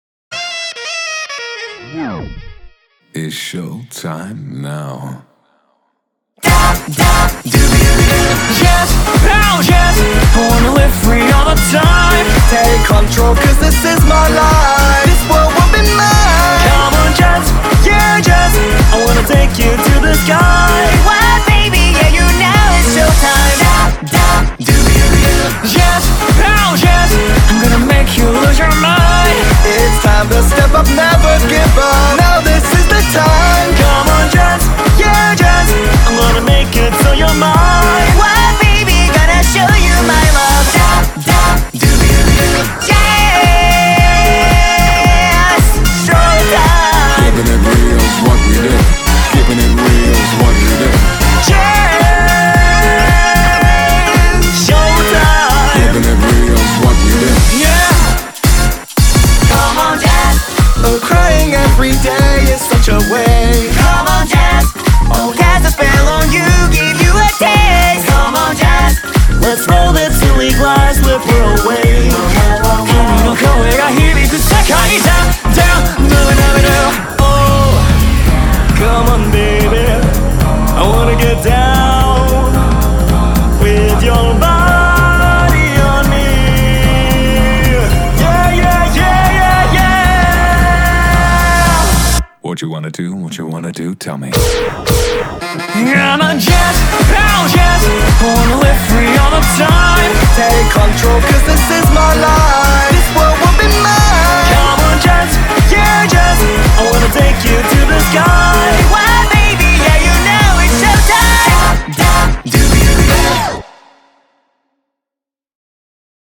BPM112
MP3 QualityMusic Cut